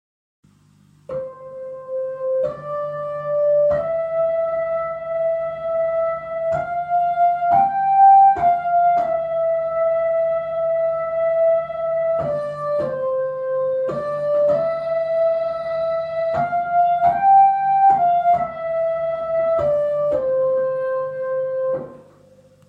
Bei meiner Hammond klingt seit einiger Zeit ein Ton unsauber, d. h. es ist eine unschöne Schwingung zu hören.
Hier eine Tonfolge mit 2 mal c d e f g und zurück. Die Tonqualität lässt sicherlich zu wünschen übrig, aber insbesondere bei 14 bis 16 sec kann man hören, was ich meine. Das ganze aufgenommen mit Handy und A102 trocken und Vibrato.